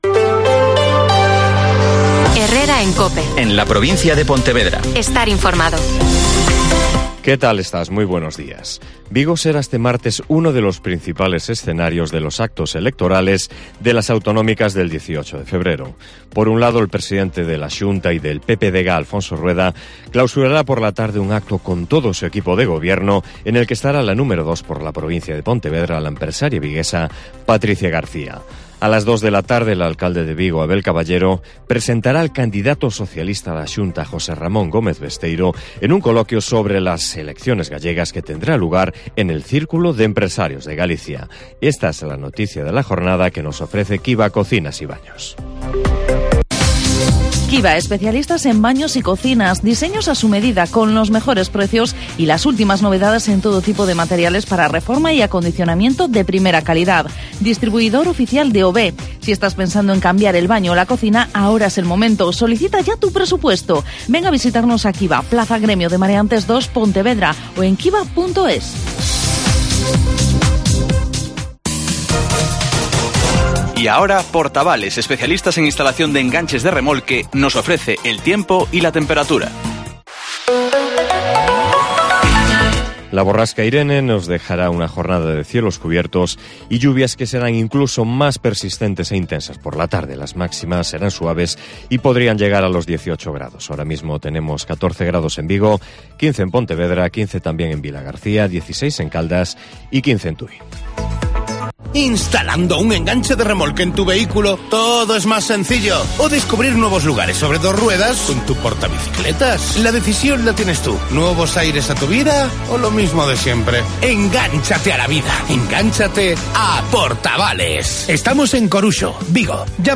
Herrera en COPE en la Provincia de Pontevedra (informativo 08:24h)